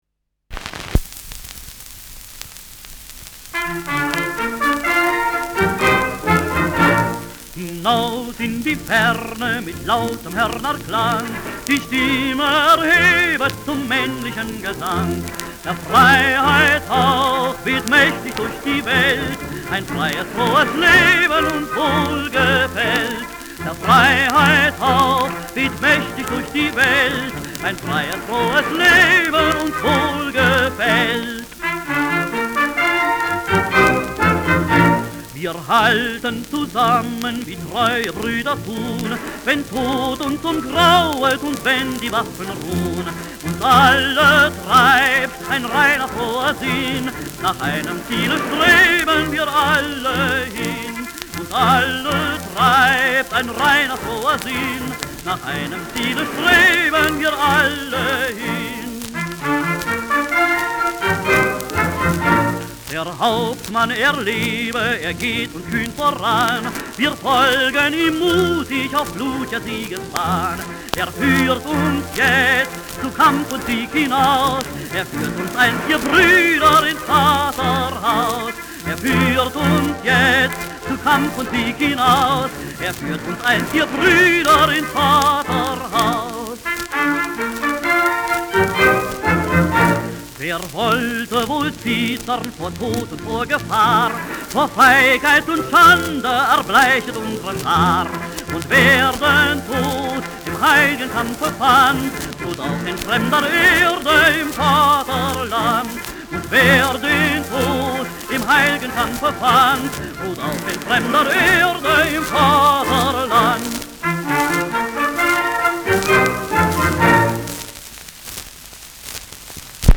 Schellackplatte
Vereinzelt leichtes Knacken